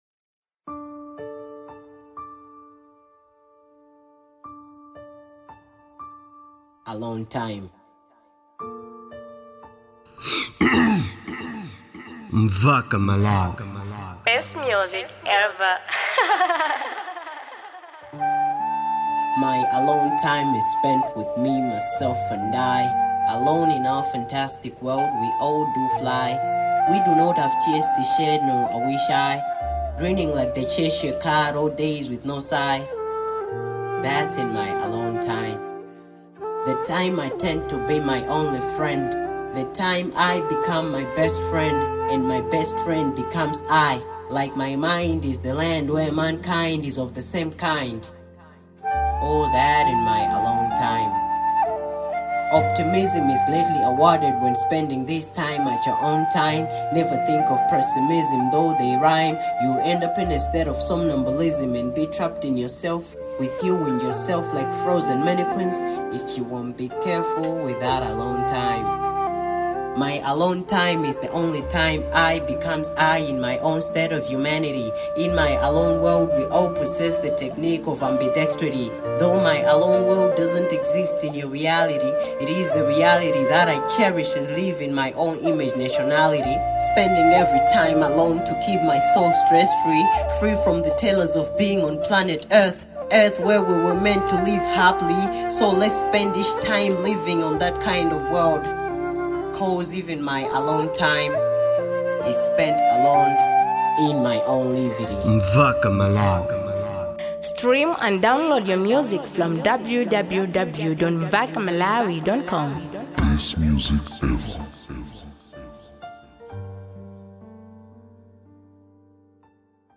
type:poem